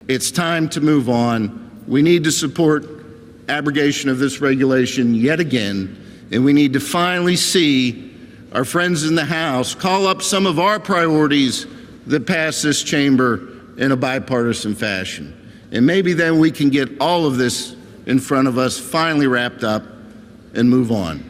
On the floor of the senate yesterday, Senator Joe Pittman said that it was finally time to put RGGI in the rearview mirror.